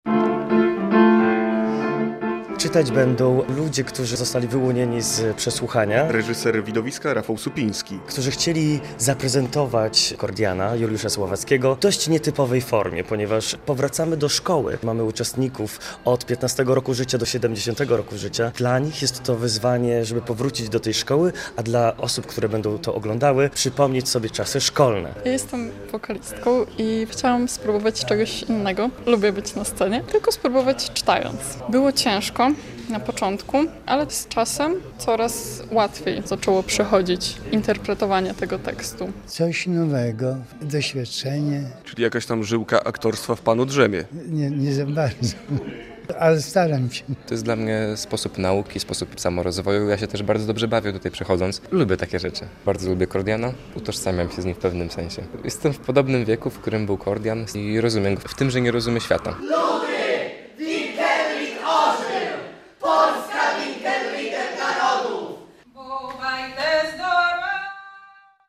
Artyści podczas próby widowiska słowno-muzycznego "Kordian", fot.
Widowisko słowno-muzyczne "Kordian" w nietypowej formie - relacja